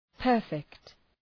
Προφορά
{pər’fekt}